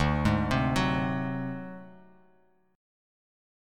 D7b5 Chord
Listen to D7b5 strummed